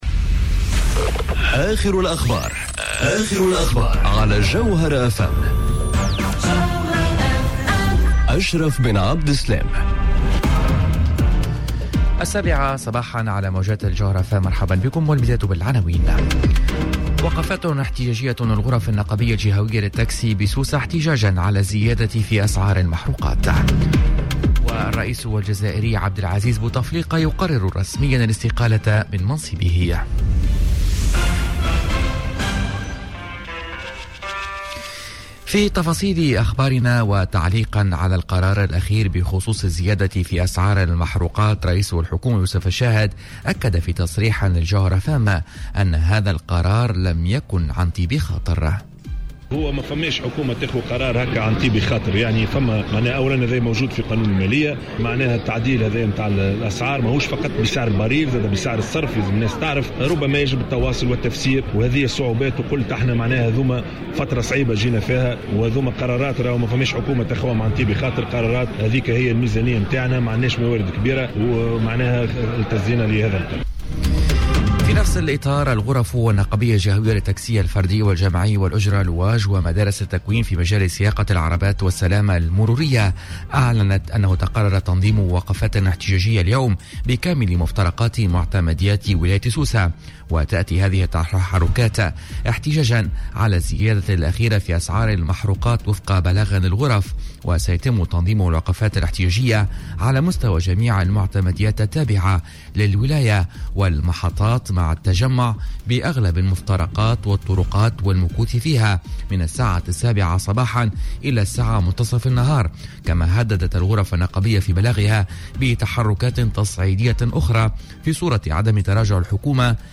نشرة أخبار السابعة صباحا ليوم الإربعاء 03 أفريل 2019